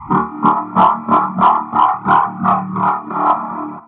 SkullLaugh.fbx
Category 🤣 Funny
ANIMATION LAUGH SKULL sound effect free sound royalty free Funny